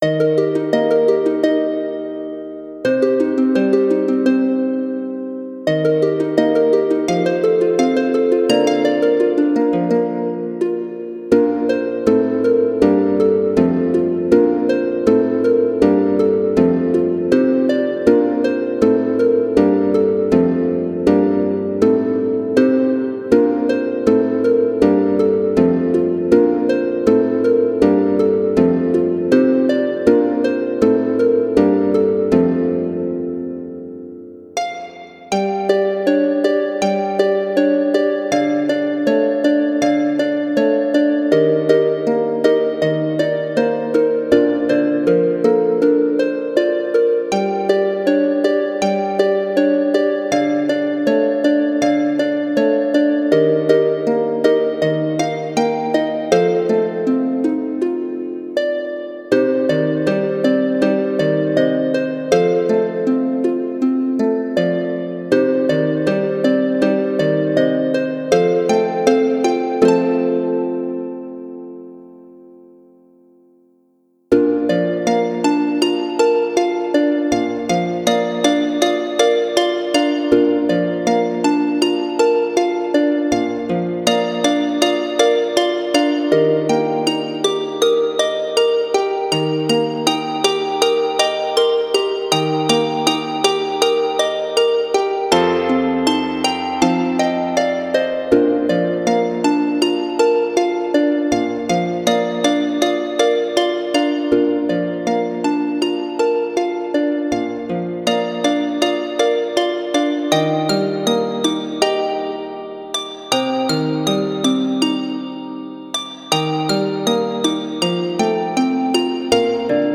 for solo lever or pedal harp
classical-sounding work